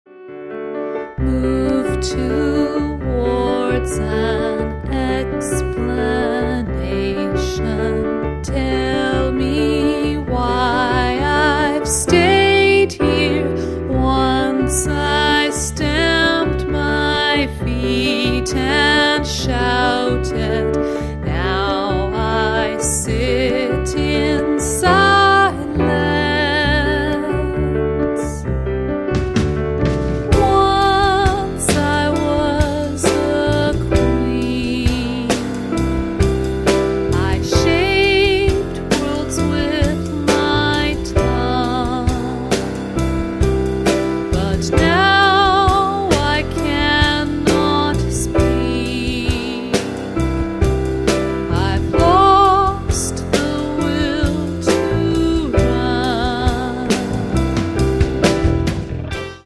piano
guitar
double bass
drums